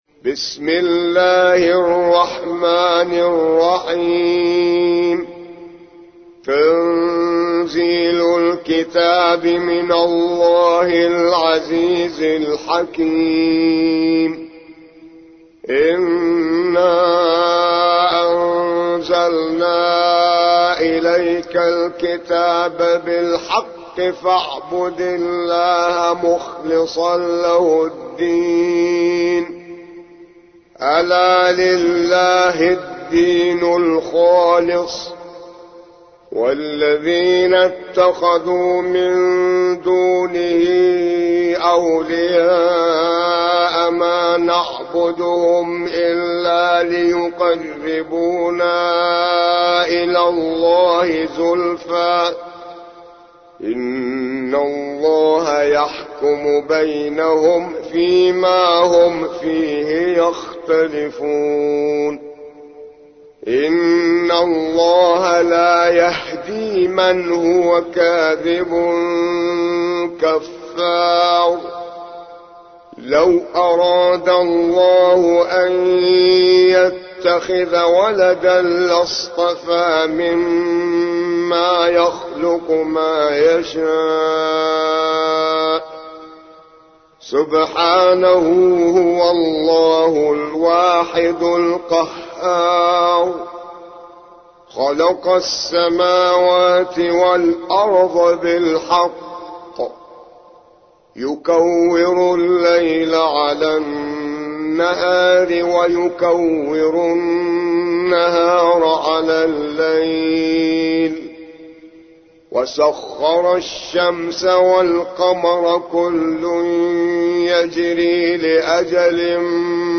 39. سورة الزمر / القارئ